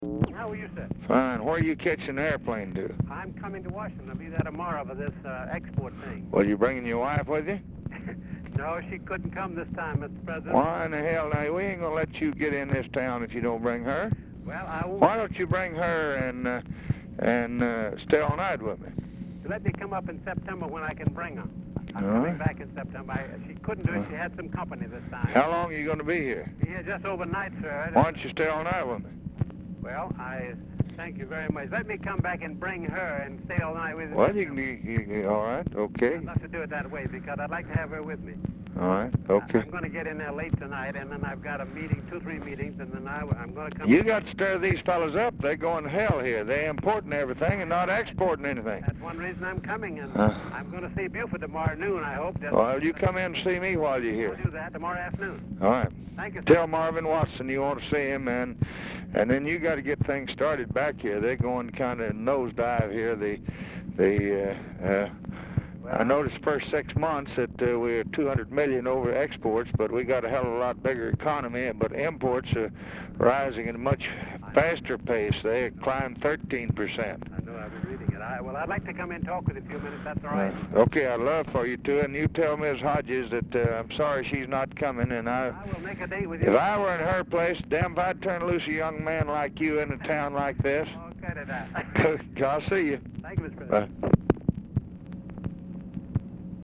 Telephone conversation # 8679, sound recording, LBJ and LUTHER HODGES, 8/30/1965, 4:45PM | Discover LBJ
Dictation belt
Oval Office or unknown location
Specific Item Type Telephone conversation